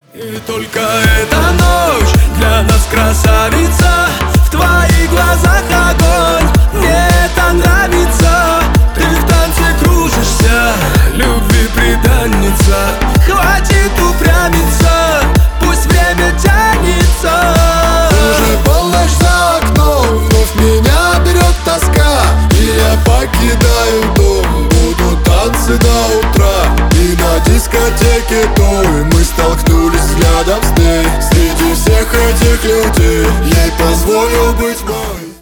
Громкие рингтоны , Танцевальные рингтоны